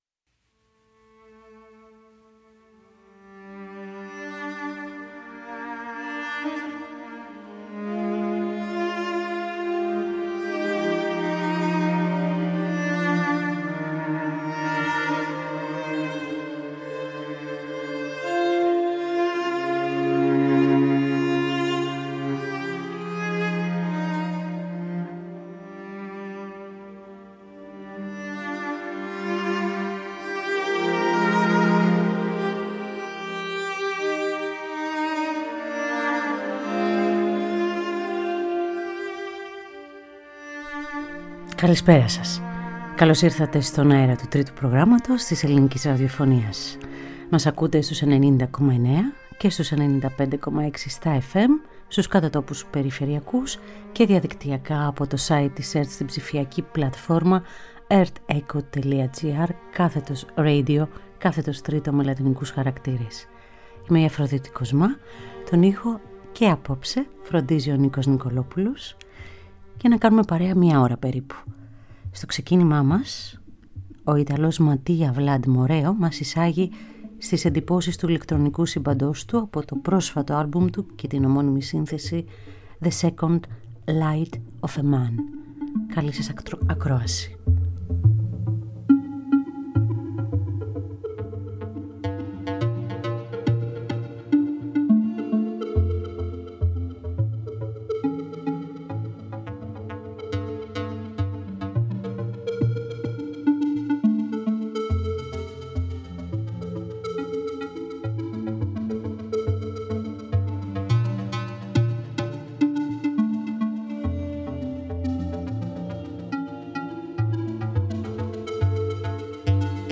την αίσθηση αιώρησης των λουπαρισμένων σαξοφωνικών ήχων
την υπναγωγική ατμόσφαιρα
το σαμανικό blues
τη συνάντηση βαρύτονου και άλτου σαξοφώνου